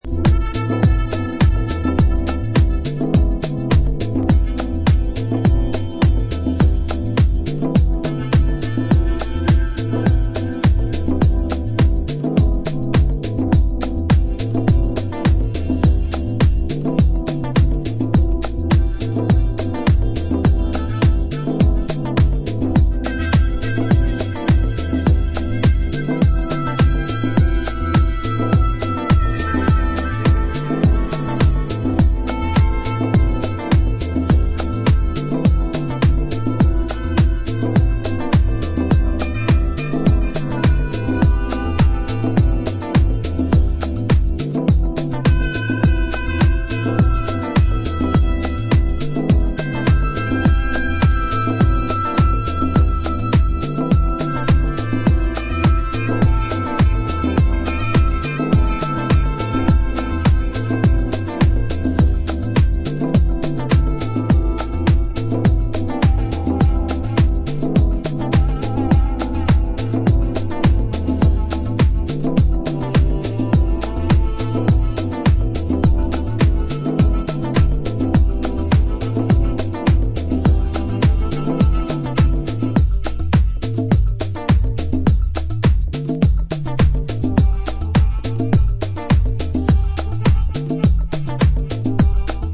90's Early House名曲と他2曲のニューバー...